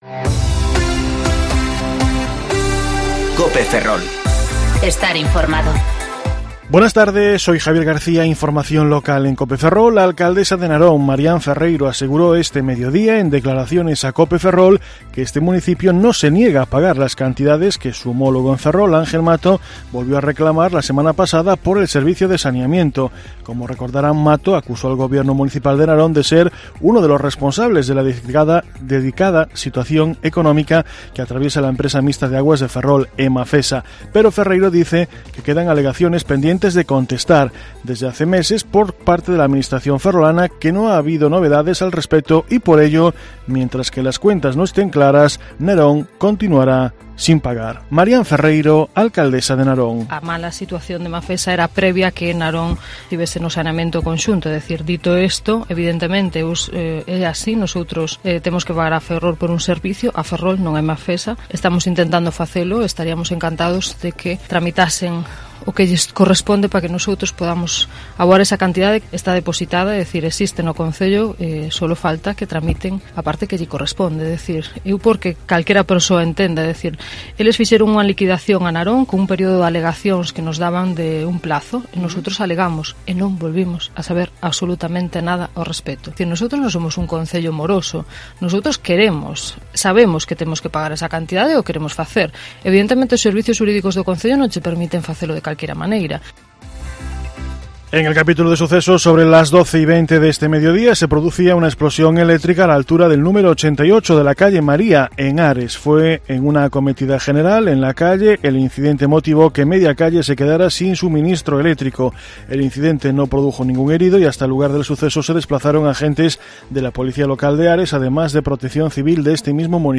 Informativo Mediodía Cope Ferrol 17/9/2019 (De 14.20 a 14.30 horas)